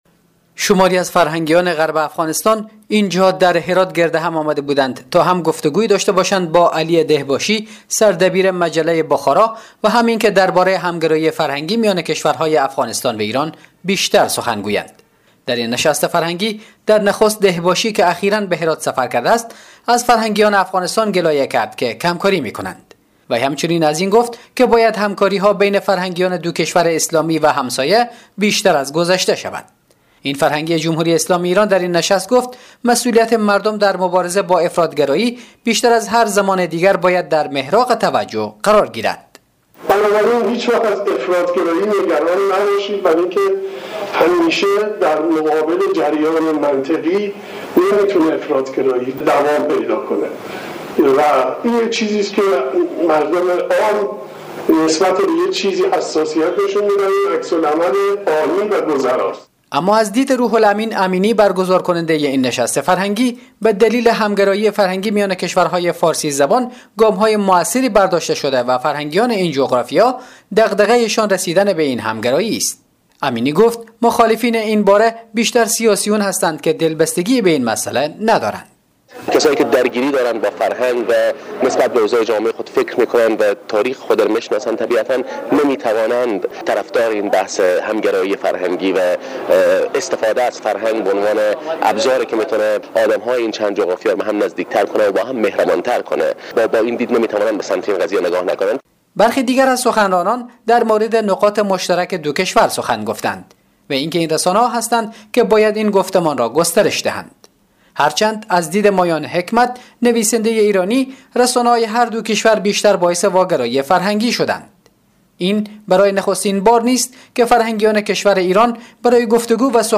جزئیات این خبر در گزارش همکارمان